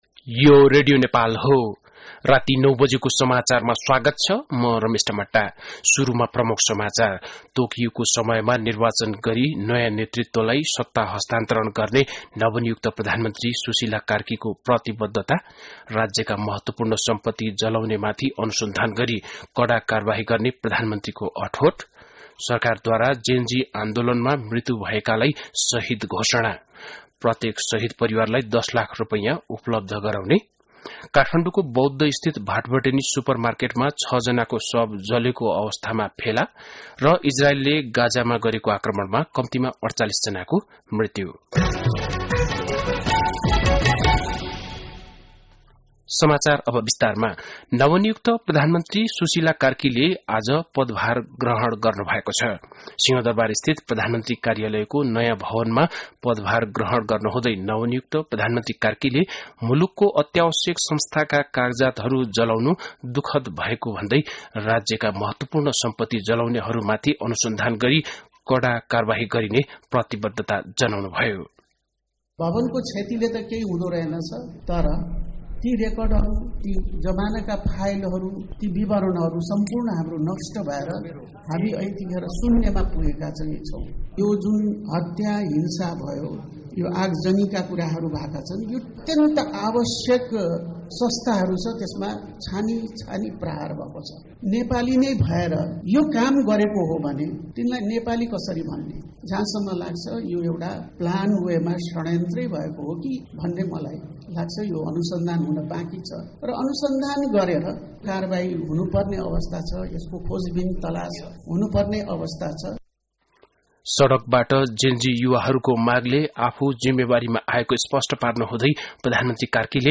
An online outlet of Nepal's national radio broadcaster
बेलुकी ९ बजेको नेपाली समाचार : २९ भदौ , २०८२
9-pm-nepali-news-5-29.mp3